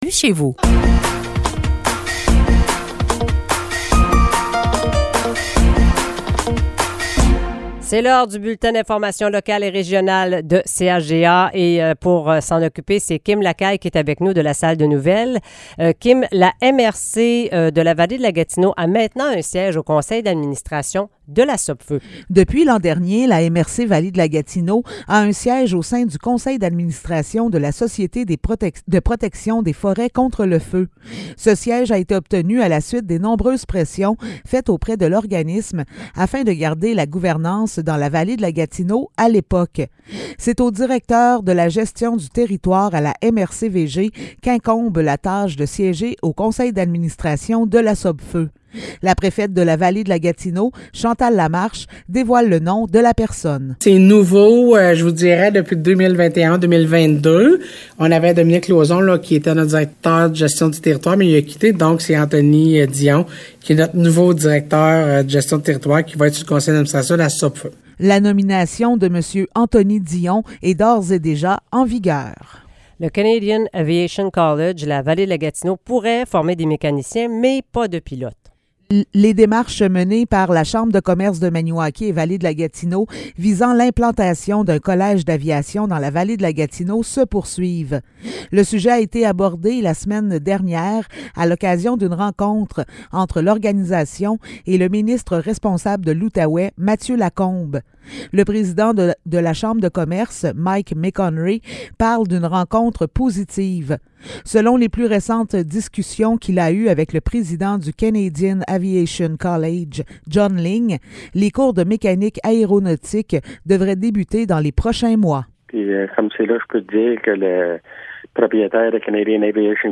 Nouvelles locales - 24 janvier 2023 - 8 h